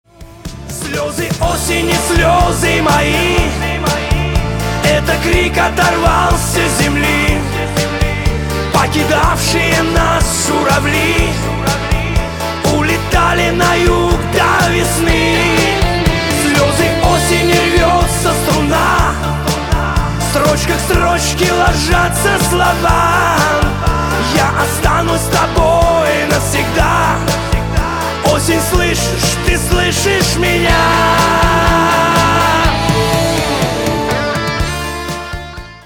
• Качество: 192, Stereo
русский шансон
шансон
блатные